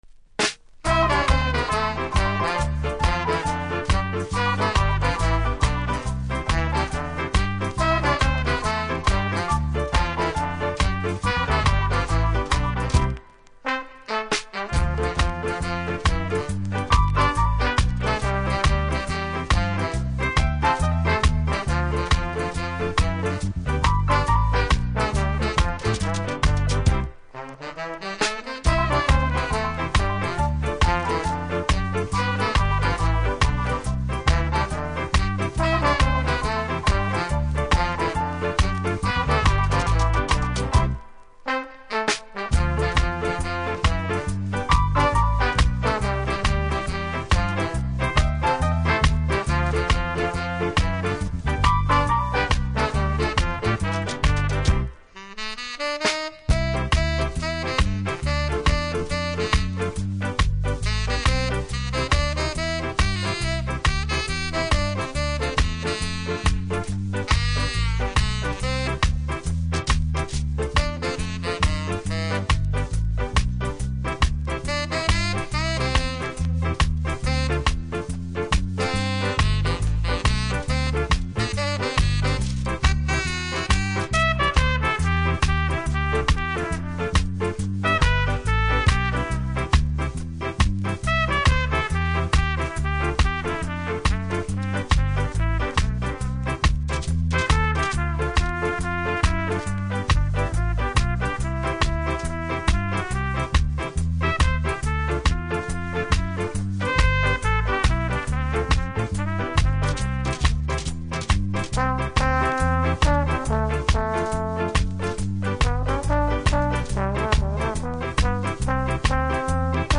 キズもノイズも少なく良好盤です。